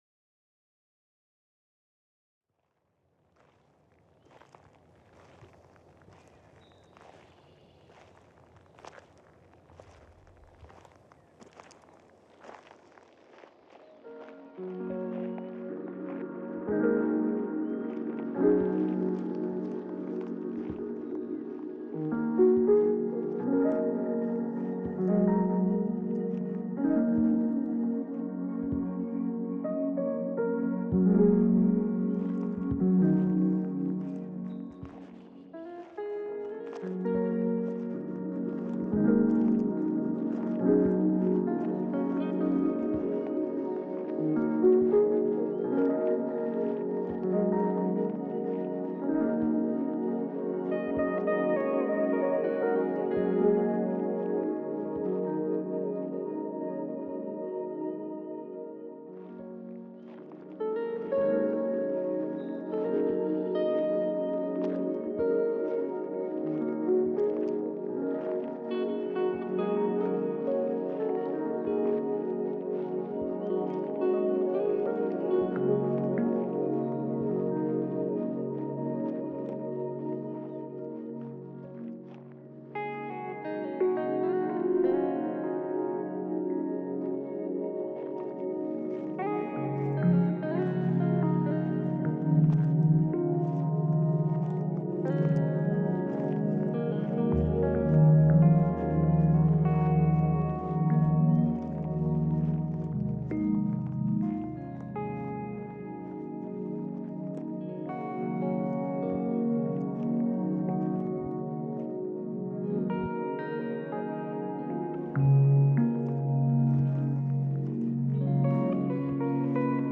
-Most instruments and samples come from Logic
-Recording little loops into the SP (with FX) using the new looper function
-Adding guitar and FX/“mastering” in Logic
Another POC, with a lot of similar sounds.